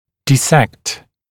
[dɪ’sekt][ди’сэкт]рассекать, вскрывать, расслаивать, разделять